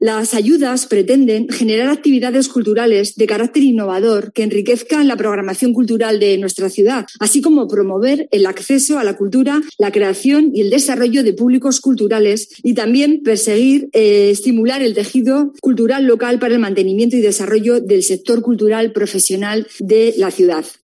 Carmen Urquía, concejala de Cultura de Logroño